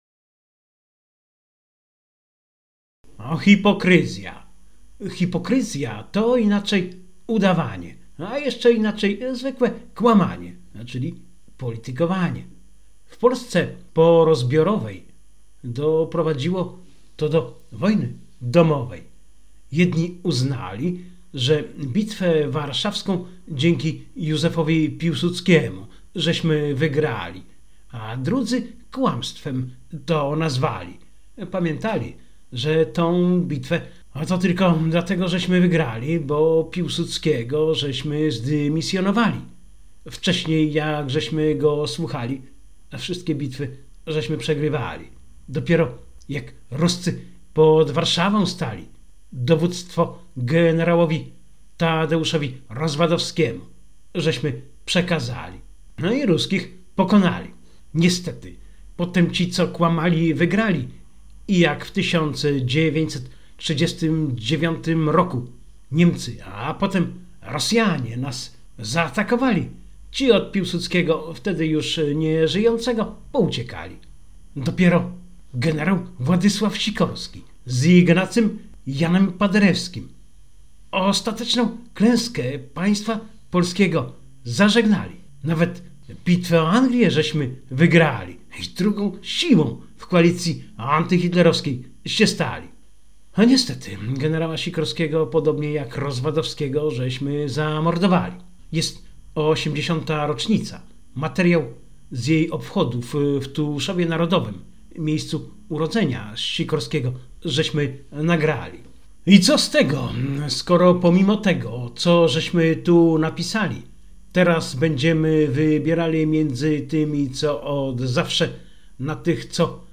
Jest 80 rocznica, materiał z jej obchodów w Tuszowie Narodowym – miejscu urodzenia Sikorskiego żeśmy nagrali.